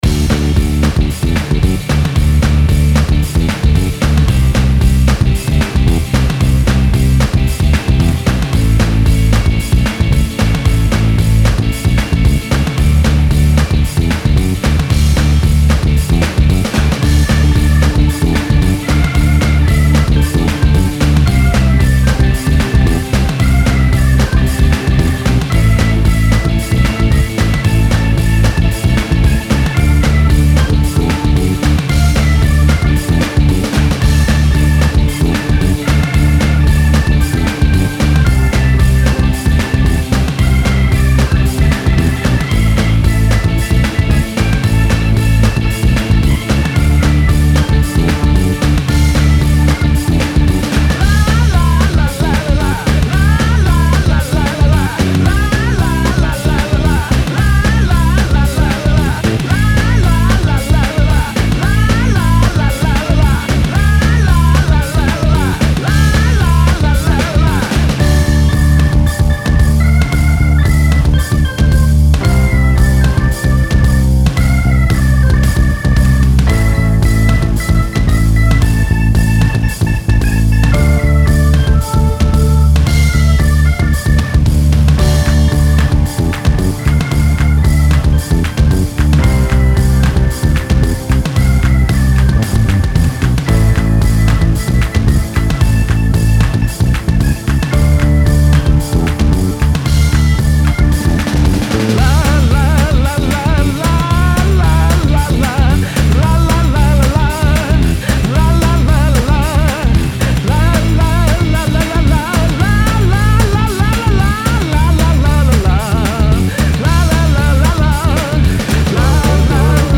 Style Style Oldies, Rock
Mood Mood Aggressive, Cool, Driving +2 more
Featured Featured Bass, Claps/Snaps, Drums +4 more
BPM BPM 110